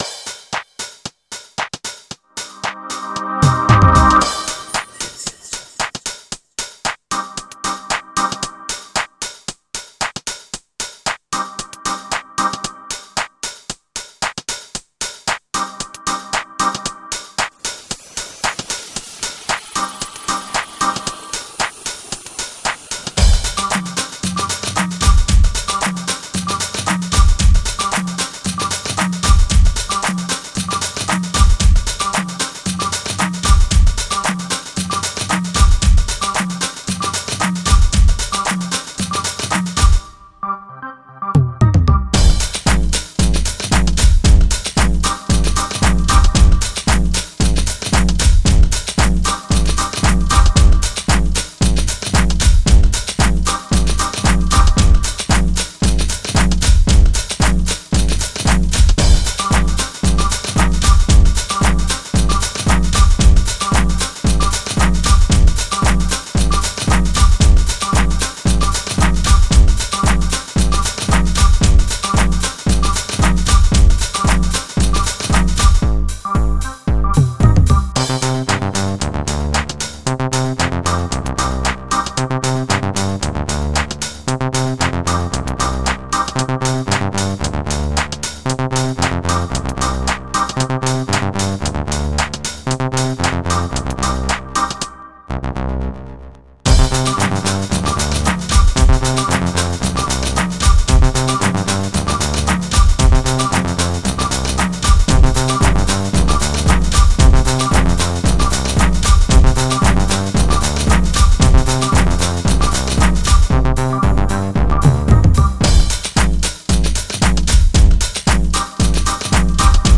Viel Acid und viel Rhythmus – das war schon prägend!